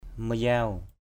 /mə-zaʊ/